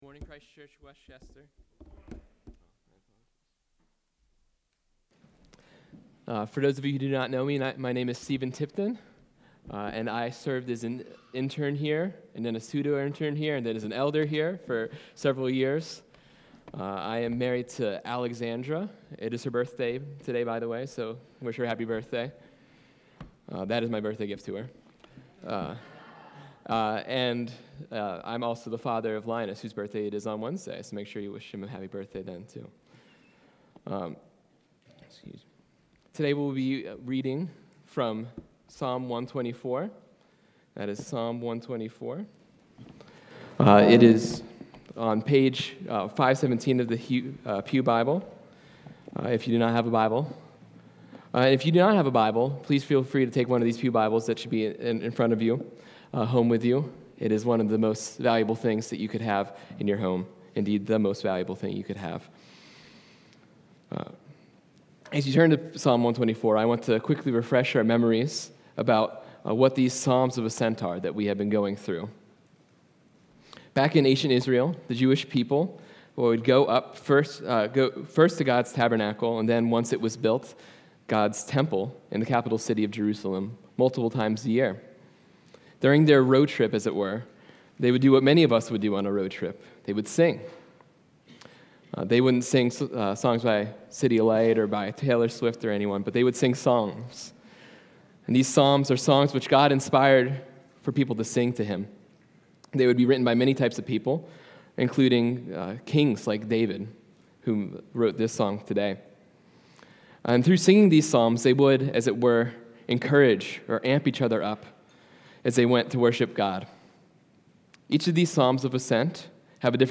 Sermon-1-11-26.mp3